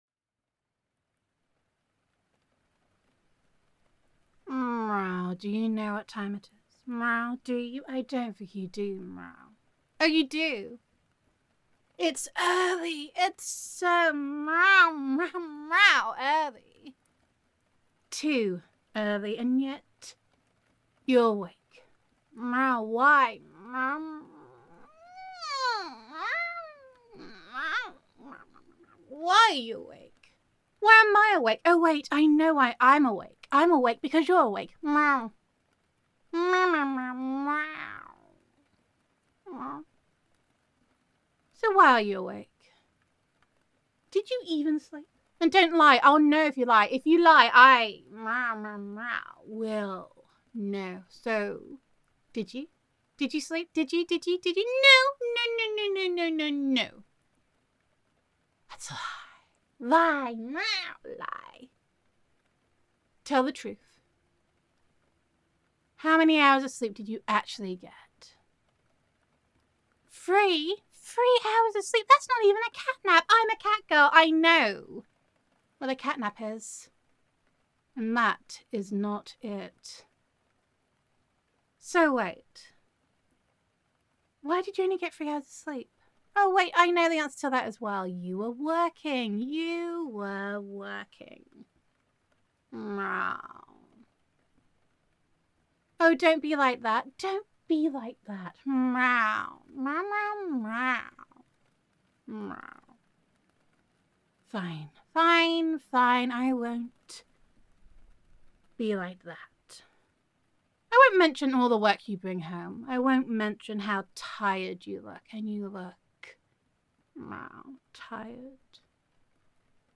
[F4A] Snuggle Prisoner [Cat Girl Roleplay][Neko Roleplay][Girlfriend Roleplay][Cat Laws][Concern][Cattitude][Sneakiness][Snuggles][Adoration][Gender Neutral][Your Cat Girl Girlfriend Thinks That You Definitely Need to Take a Cat Nap]